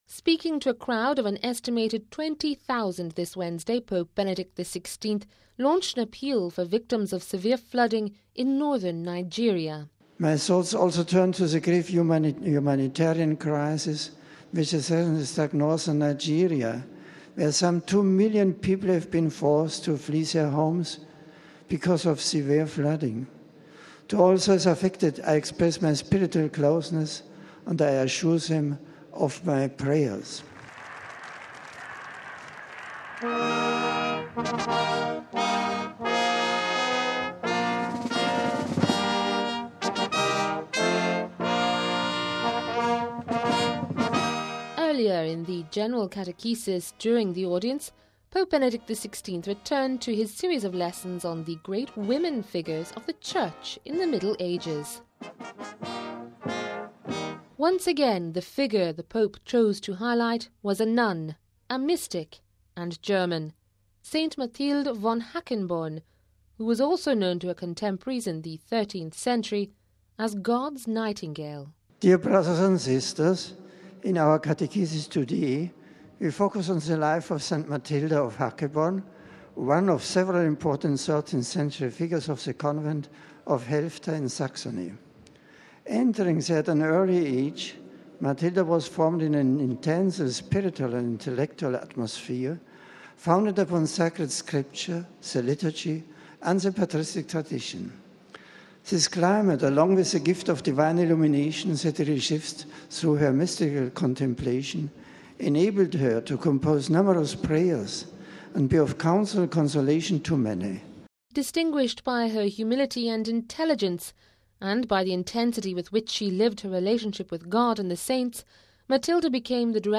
In the general catechesis during his audience this Wednesday, Pope Benedict XVI returned to his series of lessons on the great women figures of the Church in the Middle Ages. Once again the figure he choose to highlight was a nun, a mystic and German, St Mathilde von Hackeborn, who was also known to her contemporise in the 13th century as God’s nightingale.